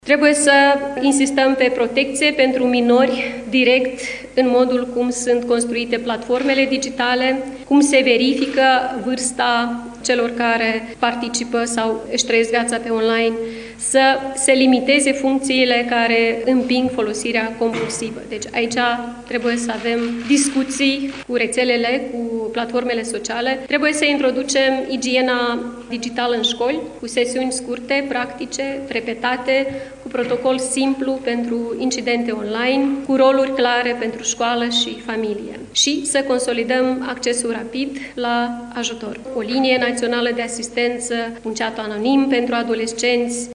Aceasta a prezentat într-o conferință de presă prioritățile președinției în acest an.